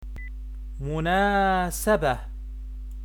This lesson is for students who interests in learning Arabic Language for practicing politics or international press in Arabic . it contains 30 of the most common words in the field of The political field in Arabic. you can read and listen the pronunciation of each word .